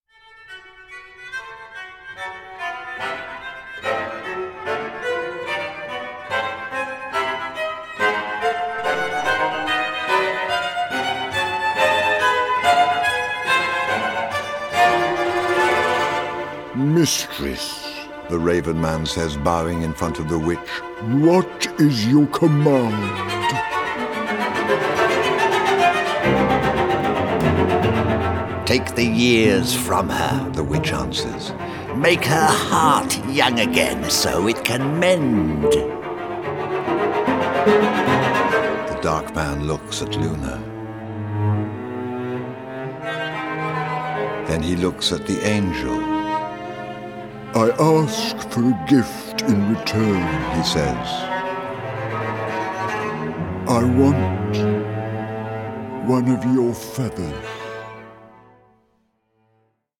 HAUNTING, GENTLE SPIRITS, DREAMS, AND LULLABIES
all above a rich bed of cellos